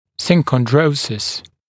[ˌsɪŋkɔnˈdrəusɪs] мн. [ˌsɪŋkɔnˈdrəusiːz][ˌсинконˈдроусис] мн. [ˌсинконˈдроуси:з]синхондроз, хрящевое соединение